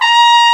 FLGLBONE.WAV